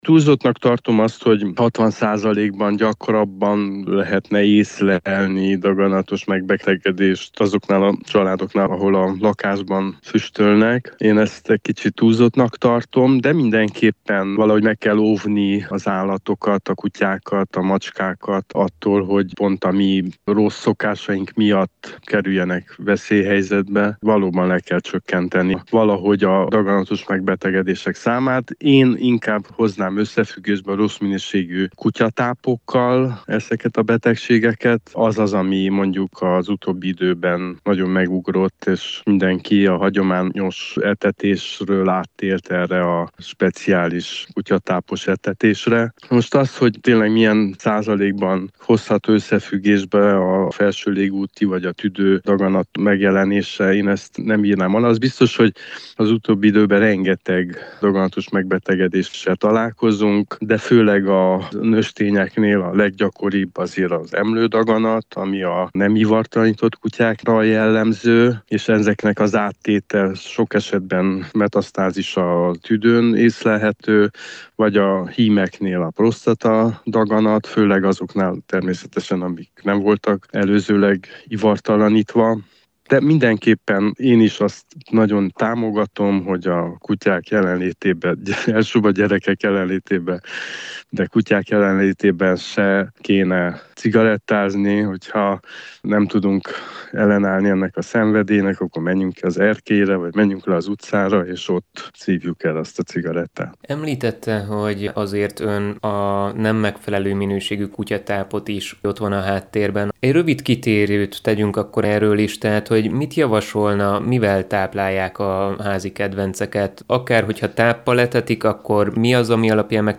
A rádiónk által megkérdezett állatorvos is mindenképp azt tanácsolja, hogy kerüljük a háziállatok közelében a dohányzást, de azt is megjegyezte, hogy az olcsó, nem megfelelő minőségű száraztápok is nagy százalékban felelősek lehetnek a háziállatok megbetegedésében.